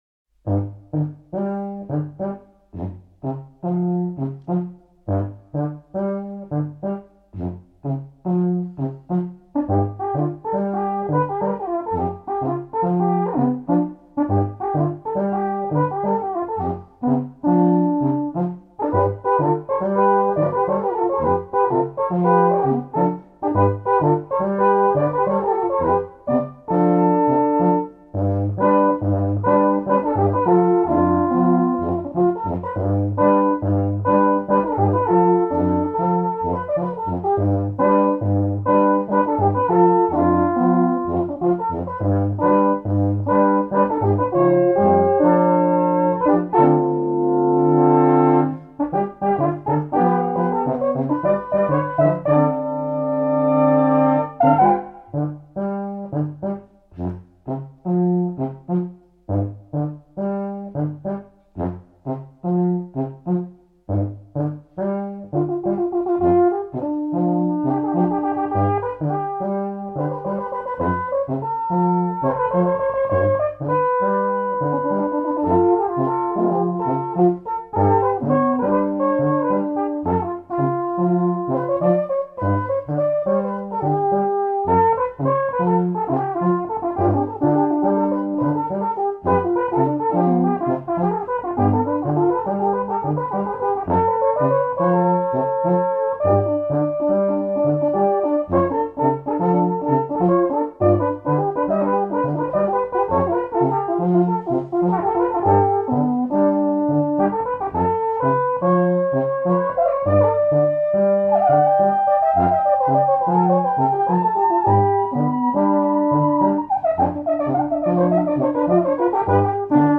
Alphornquartett (3.5 Minuten) (Noten bestellen)
Studioversion: